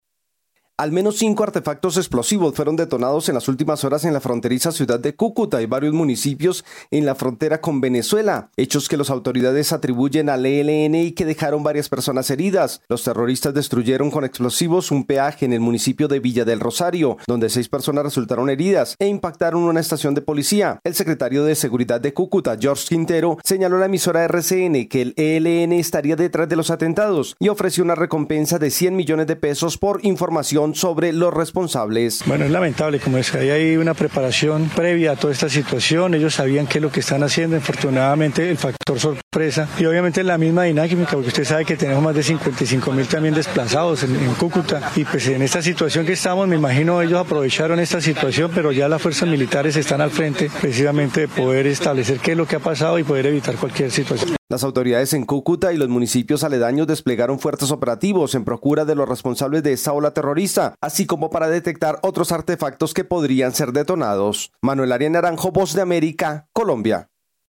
Una serie de cinco atentados del ELN en Cúcuta y sus alrededores confirma una situación de violencia en Colombia. Desde Colombia informa el corresponsal de la Voz de América